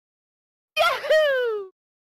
Yahoo (Mario) Sound Effect sound effects free download
Funny sound effects for comedy free download mp3 Download Sound Effect Home